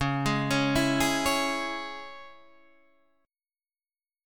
C#m chord